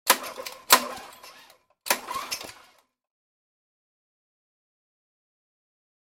Атмосферный звук косьбы травы газонокосилкой для театра